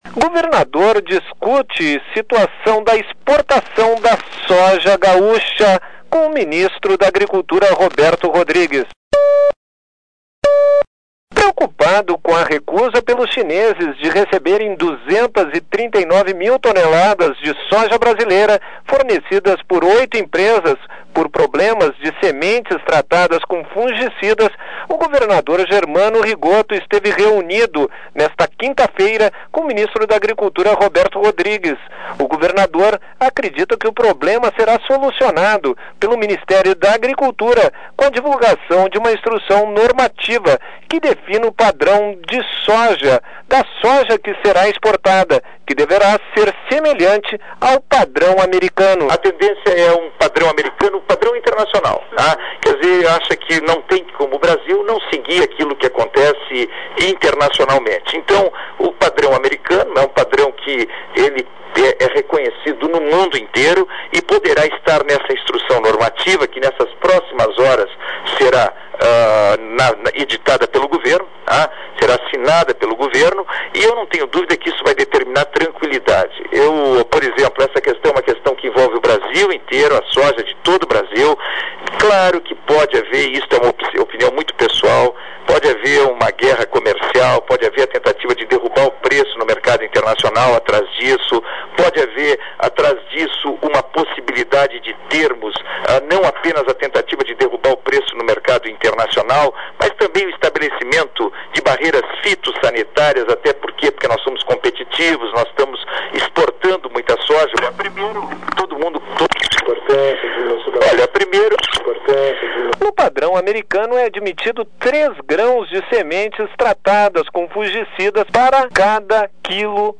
O governador Germano Rigotto esteve reunido nesta 5ª feira, em Brasília, com o ministro da Agricultura, Roberto Rodrigues, para discutir a situação da exportação da soja gaúcha. Sonora: governador Germano Rigotto, em Brasília.